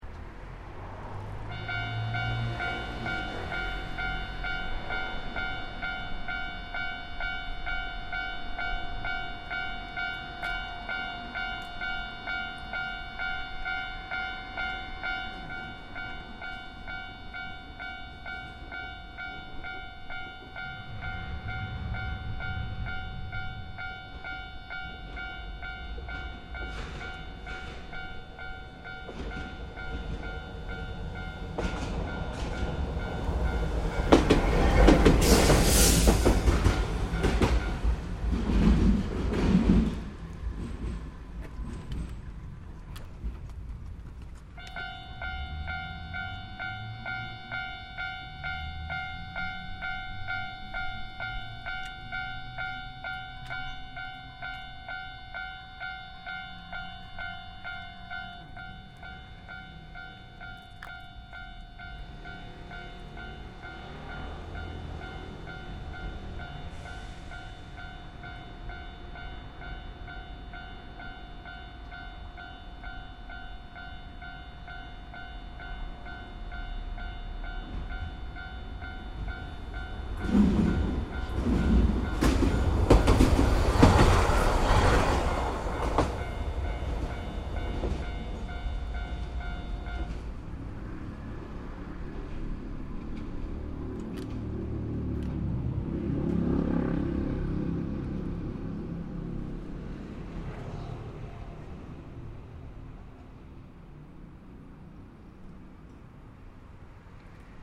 Warning beep and train driveby, Japan
Warning beeps at a train crossing, followed by a two-train flypast in Osaka, Japan.